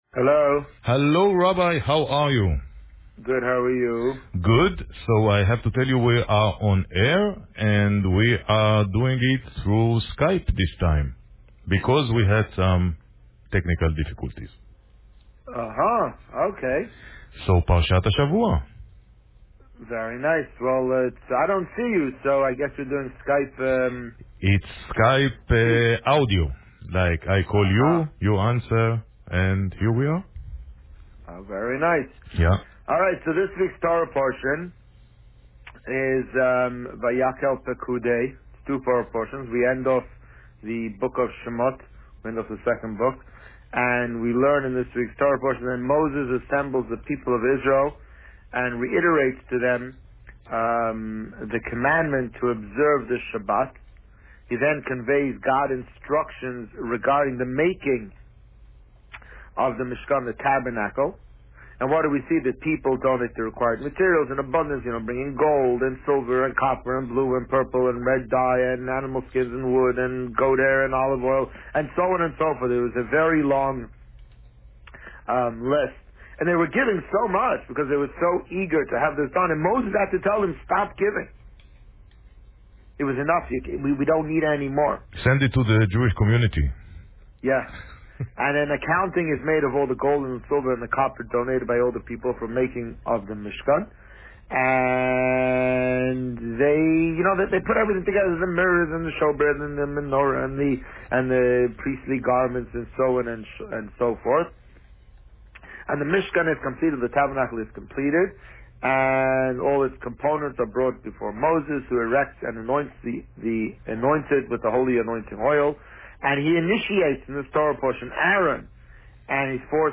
This week, the Rabbi spoke about the tragic terrorist attack in Great Britain, and the current Parsha Vayakhel-Pekudei. Listen to the interview here.